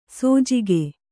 ♪ sōjige